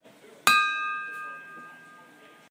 丁烷龙头
描述：轻拍几乎空的丁烷瓶